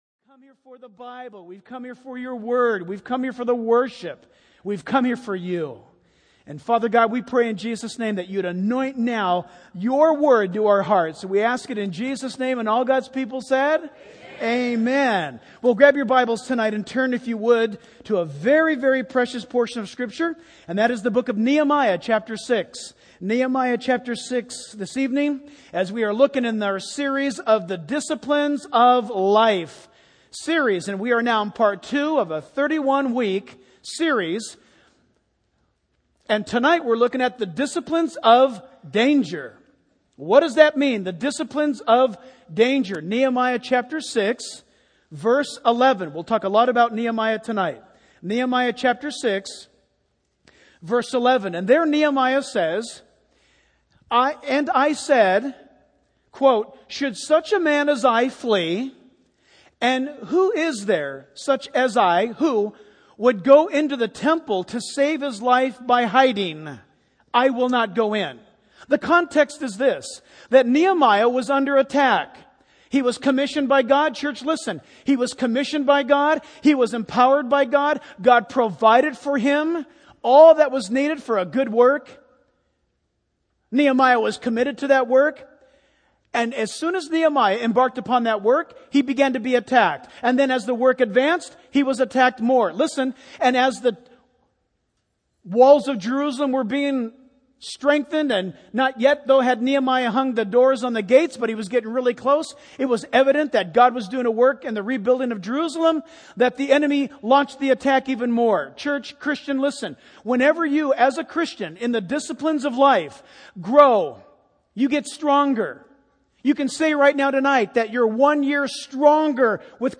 In this sermon, the preacher discusses the disciplines of danger that Christians should be aware of in the last days. He emphasizes the importance of the logos of God, which is the word of God found in the Bible.